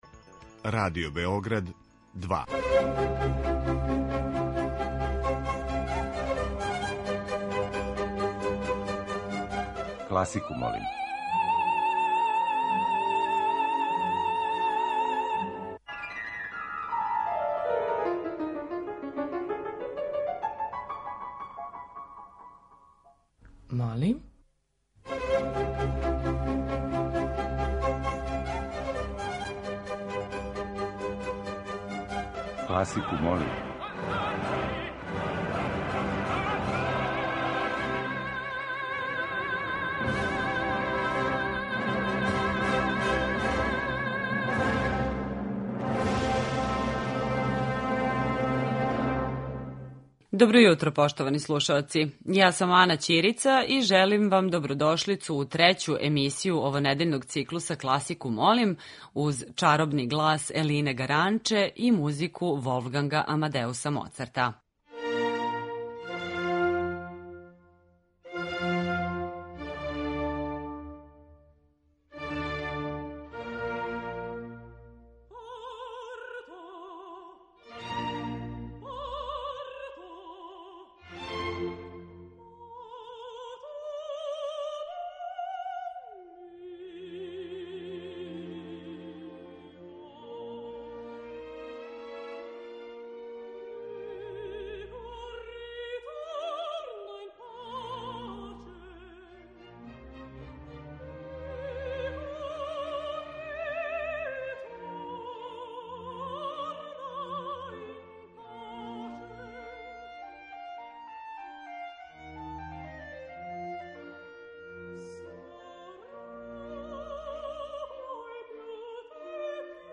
Избор за топ-листу класичне музике Радио Београда 2
Стилски и жанровски разноврсни циклус намењен и широком кругу слушалаца који од понедељка до четвртка гласају за топ листу недеље.